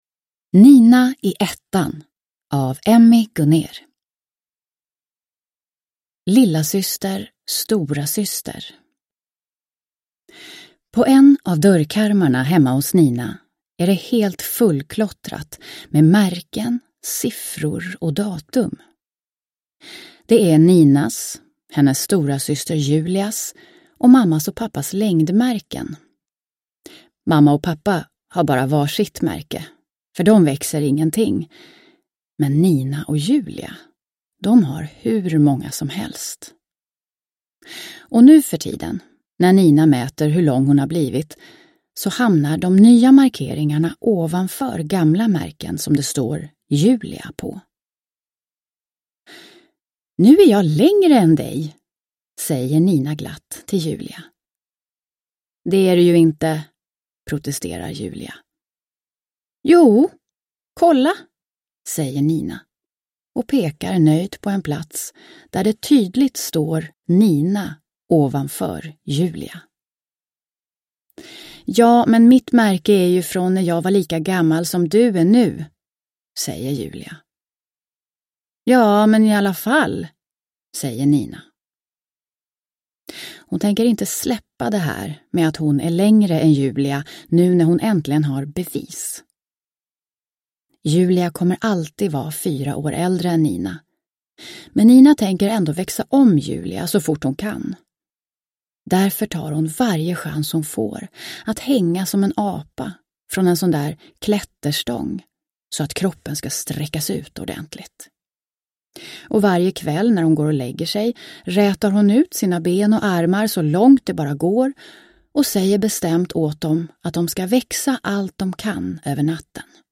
Nina i ettan – Ljudbok – Laddas ner